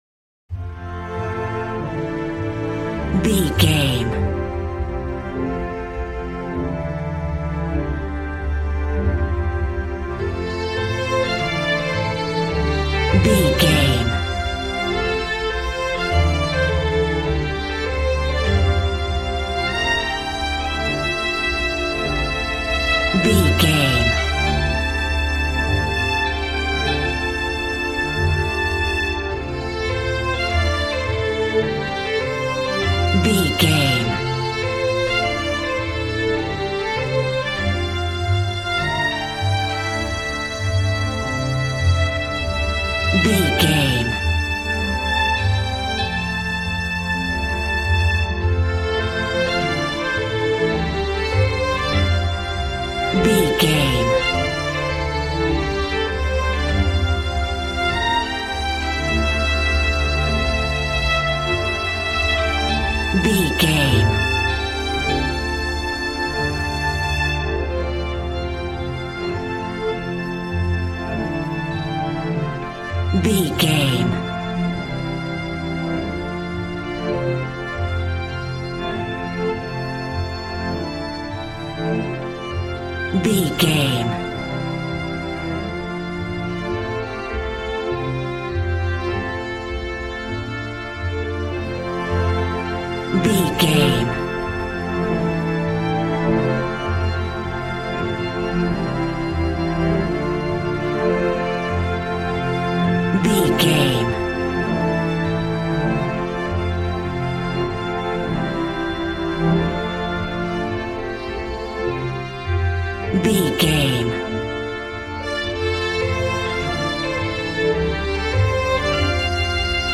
Aeolian/Minor
joyful
conga